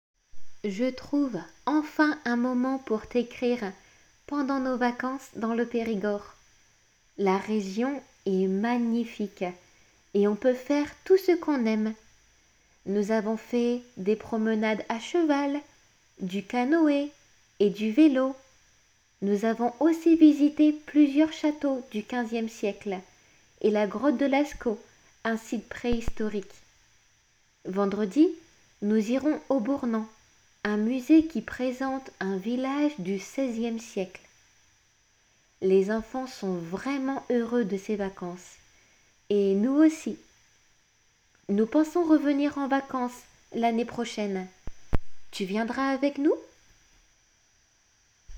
普通の速さで